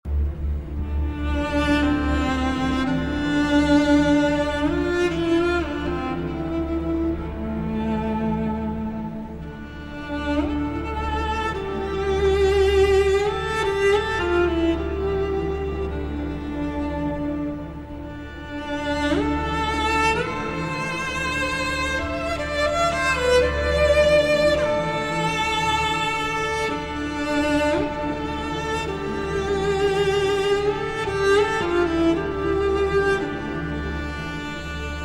Strings 5